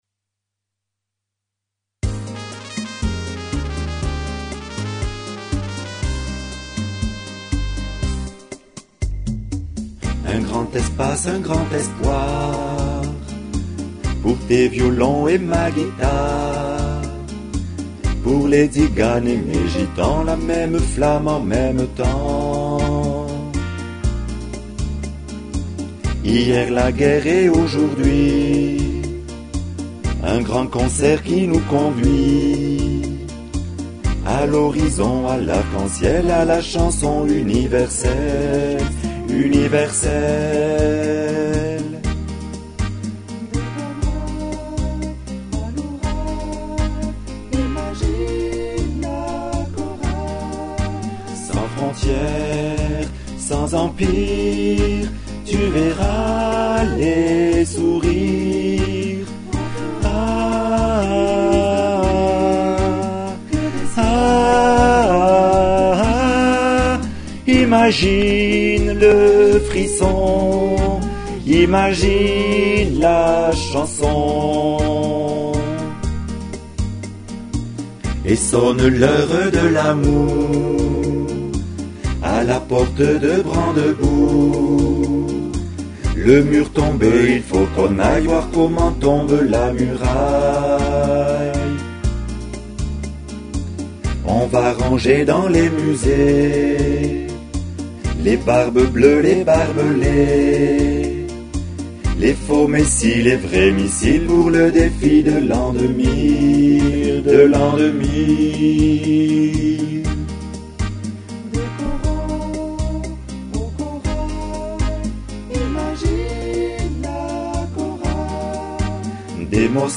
des-cornouailles-a-loural-homme.mp3